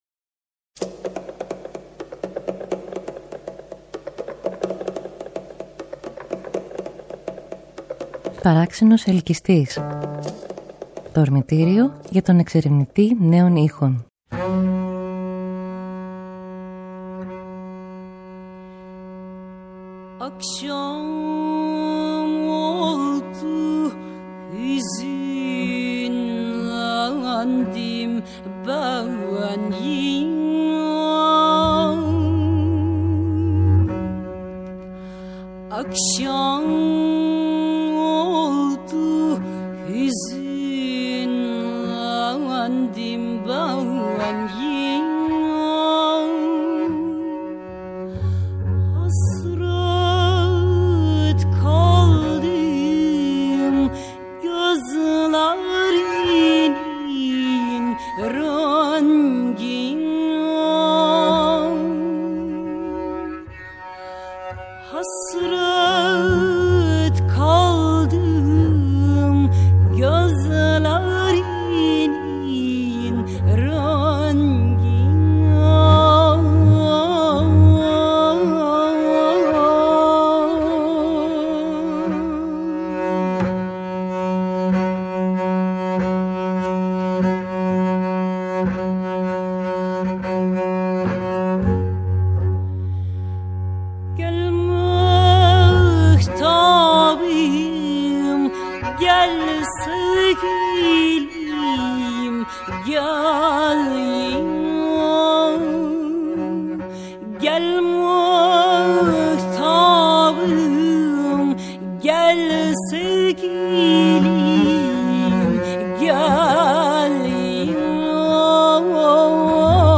Απόψε ο ηχότοπος του παρΑξενου_ελκυστΗ γίνεται πλοίο και ξεκινάει ένα ταξίδι που θα διαρκέσει όλο τον Αύγουστο, με ιστορίες της θάλασσας και ήχους υδάτινους: με αφετηρία τα νερά μας, τη θάλασσα της Μεσογείου, και τα νησιά της.